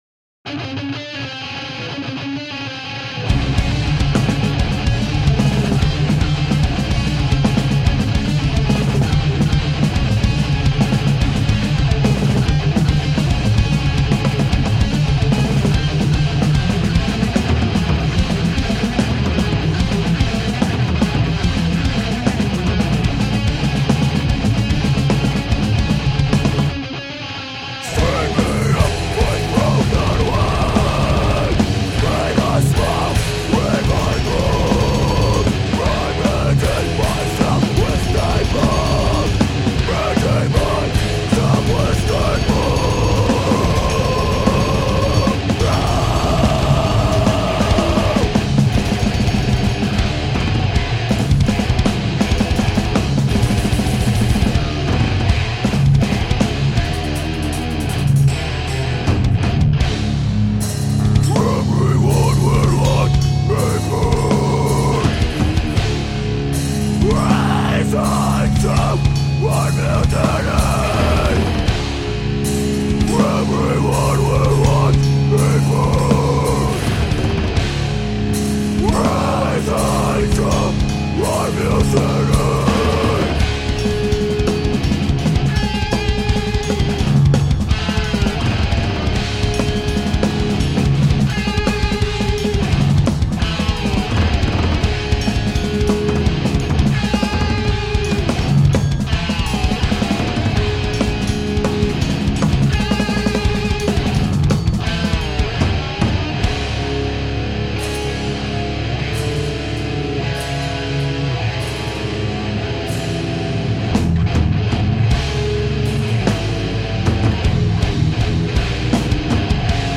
vocals
guitar
bass
drums Filed under: Metal